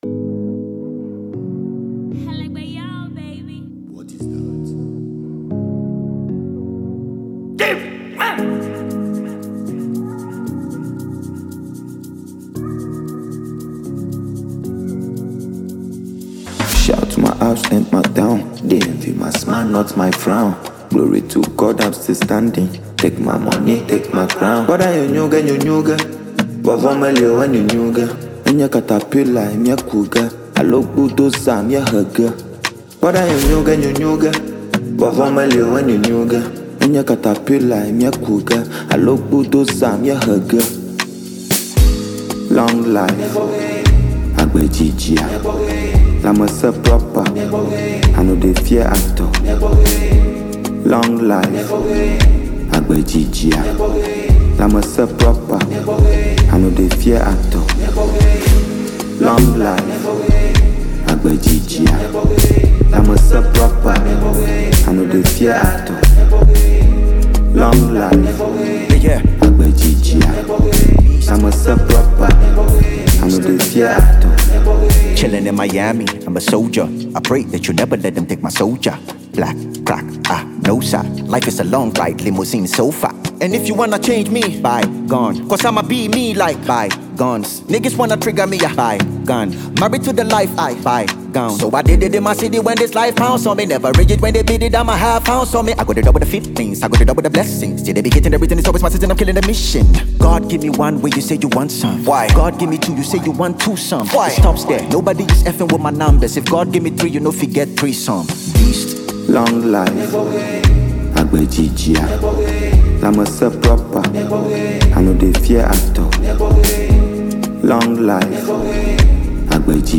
Ghana Afrobeat MP3